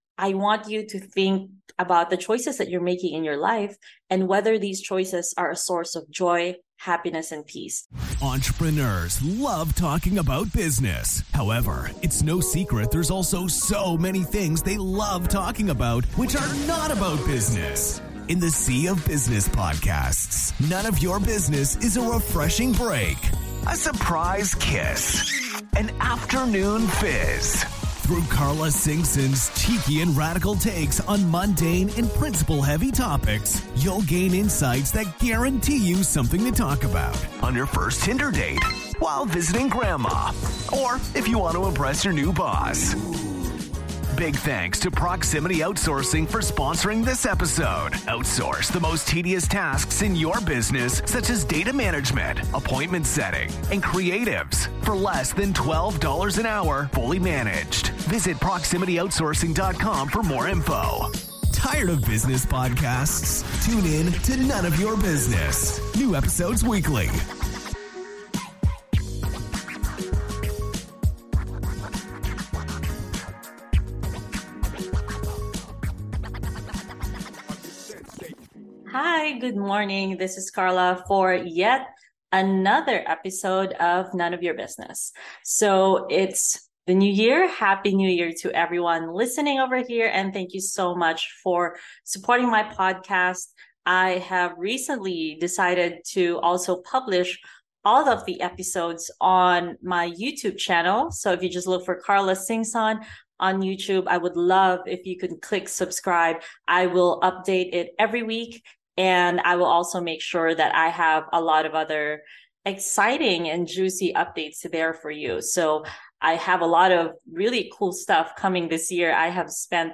In this special solo episode, I shared about my top 10 learnings and reflections from 2022.